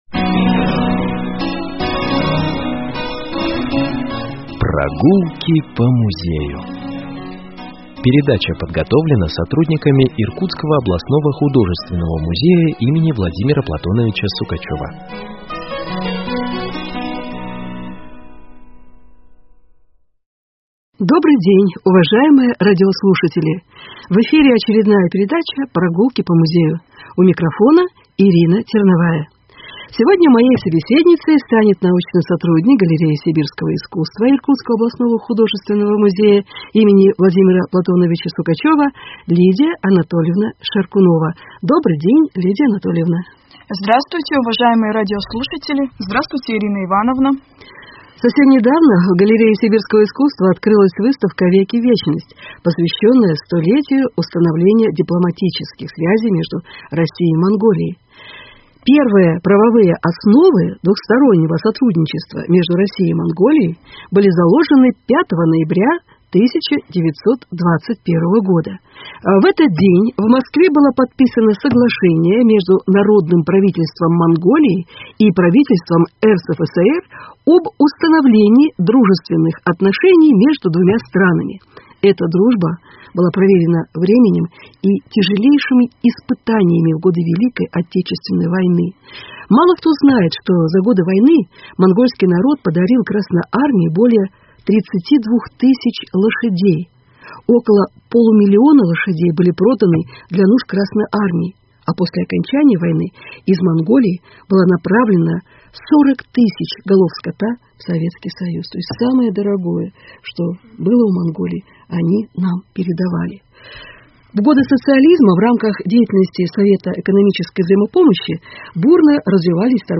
Передача
беседа